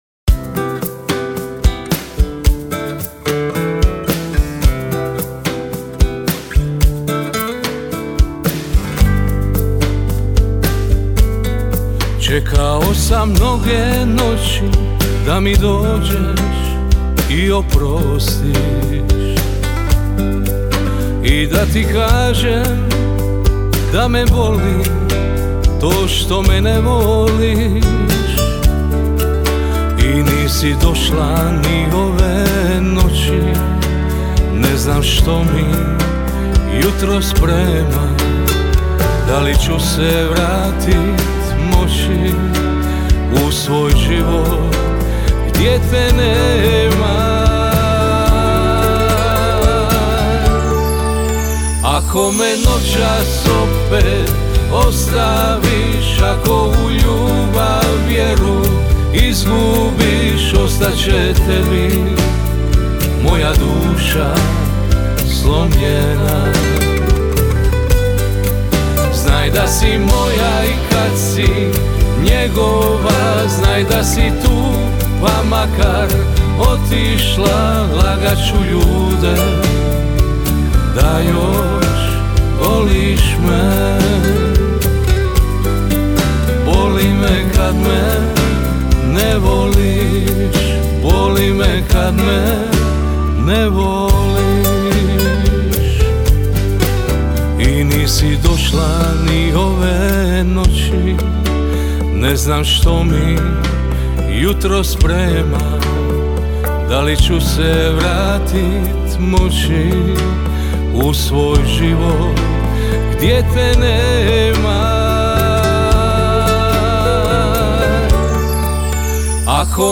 Žanr Pop